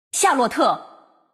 王者荣耀_人物播报_夏洛特.mp3